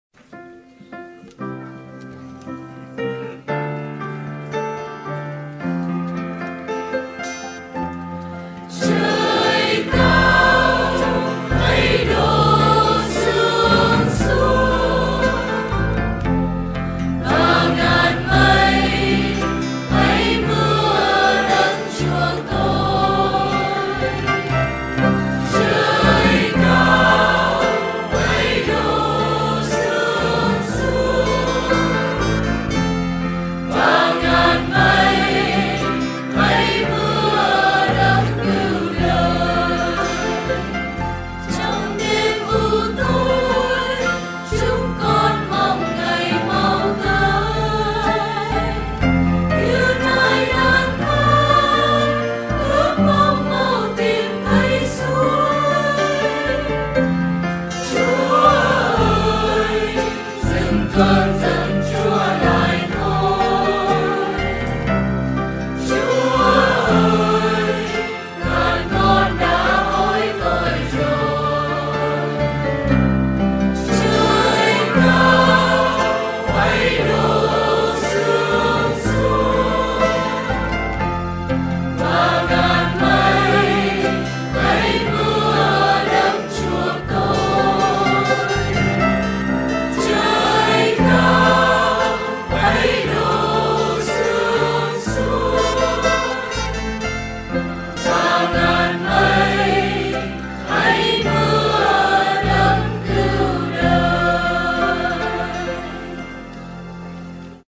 (quality không tốt lắm nhưng có còn hơn không)